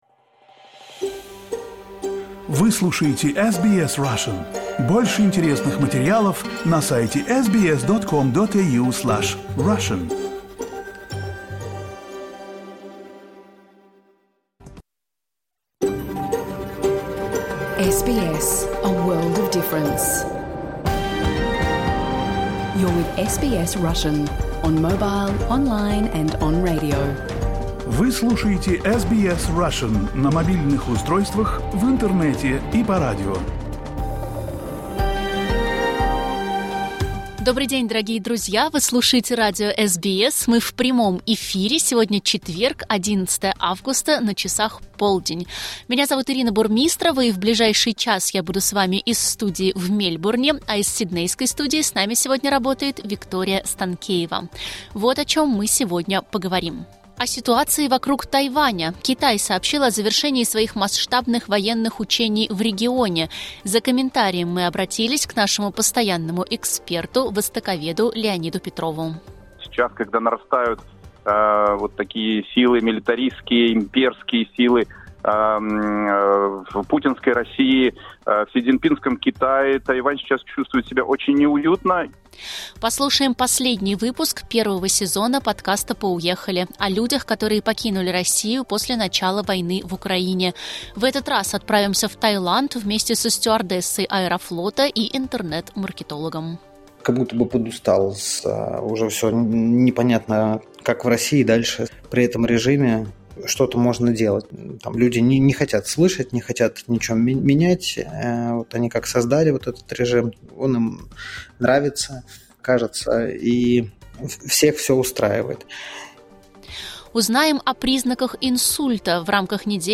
Если вы пропустили эфир, не расстраивайтесь: теперь его можно послушать целиком без внутренних рекламных блоков в наших подкастах. Выпуск новостей в этом подкасте не представлен, так как он публикуется отдельным подкастом.